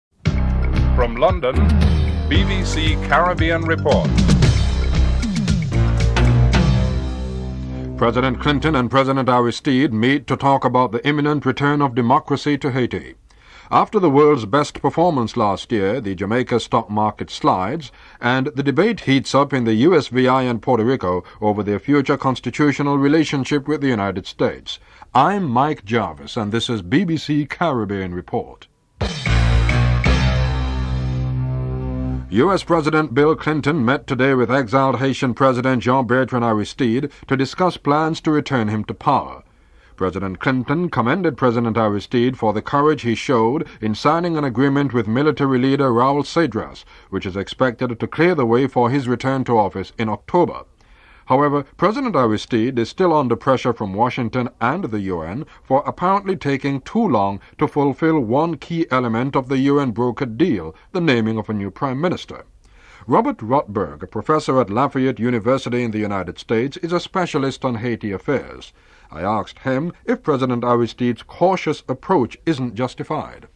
1. Headlines (00:00-00:39)